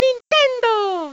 Mario says Nintendo